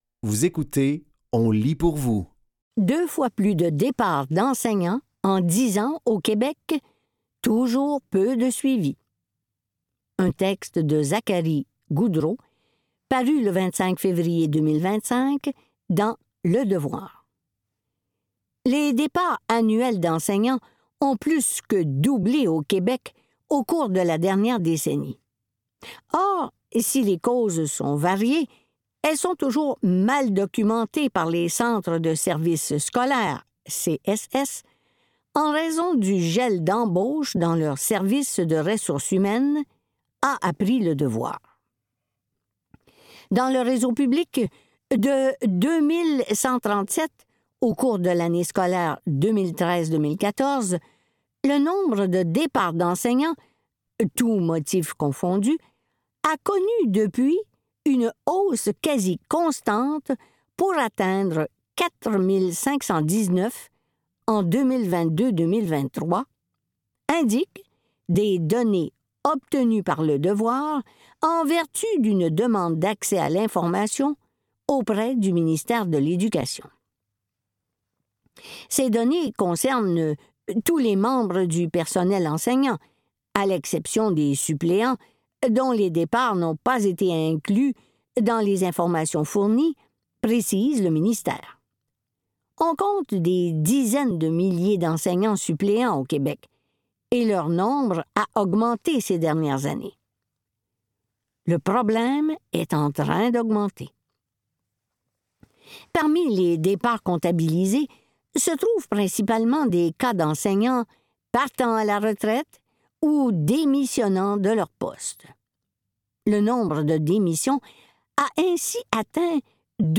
Dans cet épisode de On lit pour vous, nous vous offrons une sélection de textes tirés des médias suivants: Axelle et ONU Femmes.